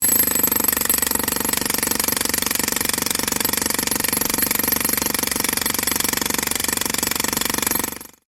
Free SFX sound effect: Jackhammer.
Jackhammer
yt_FIR6_5S2g7s_jackhammer.mp3